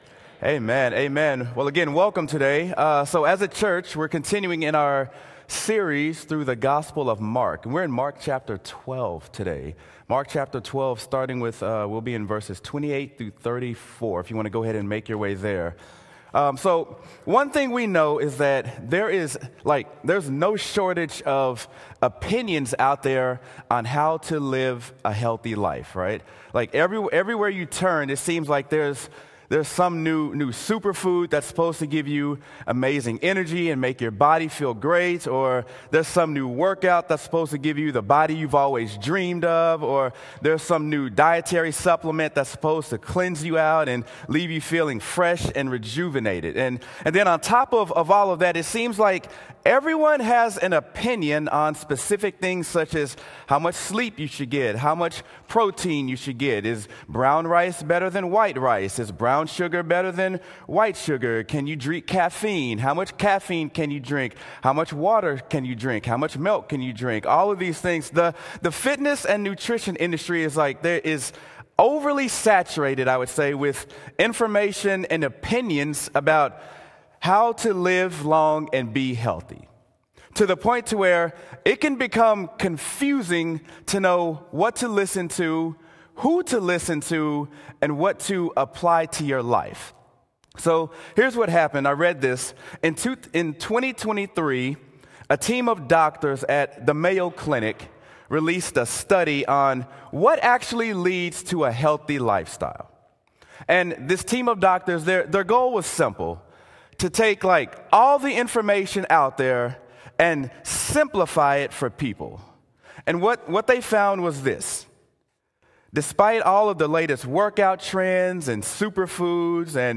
Sermon: Mark: The Two Most Important Things You Will Ever Do | Antioch Community Church - Minneapolis